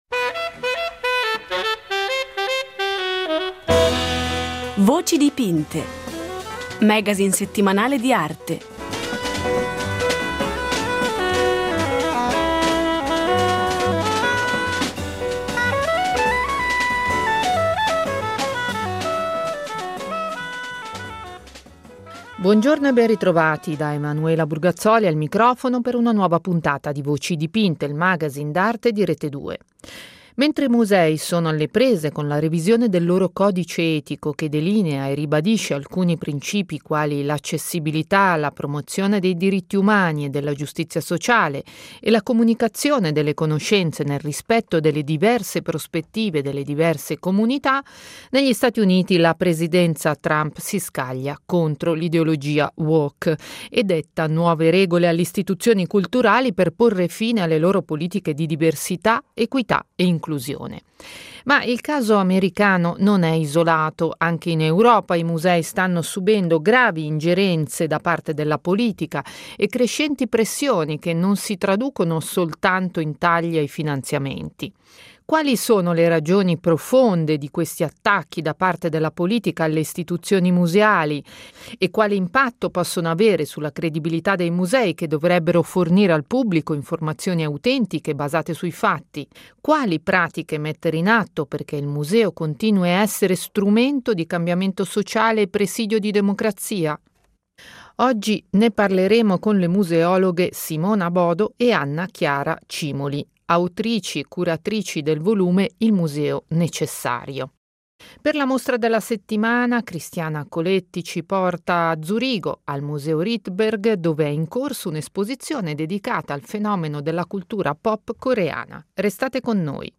Voci dipinte ne ha parlato con due ospiti: le museologhe